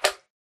slime1.ogg